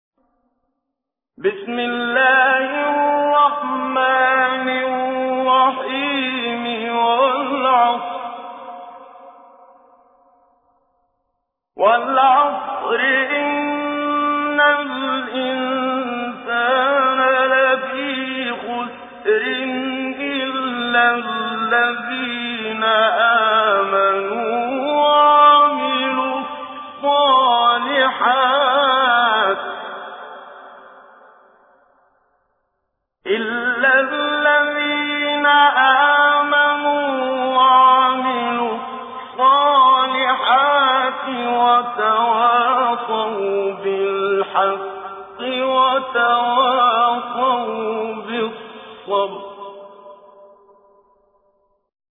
تجويد
سورة العصر الخطیب: المقريء الشيخ محمد صديق المنشاوي المدة الزمنية: 00:00:00